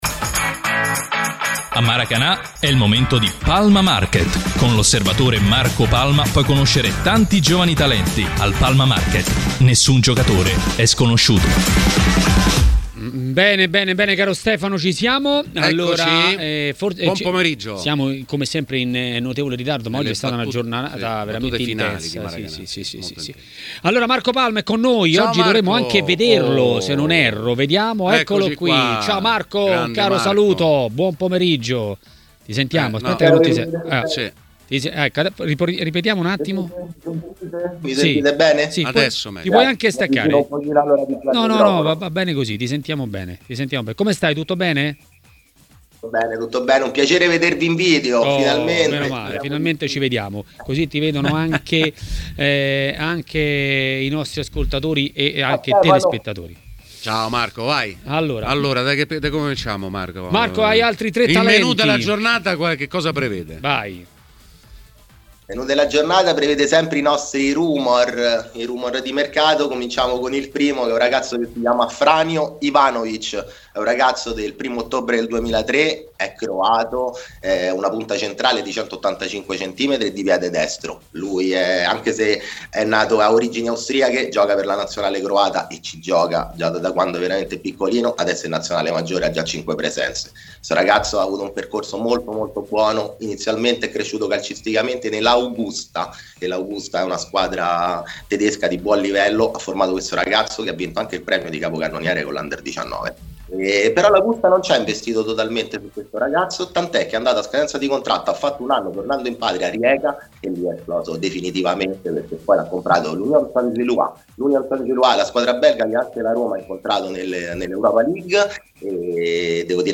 A Maracanà, nel pomeriggio di TMW Radio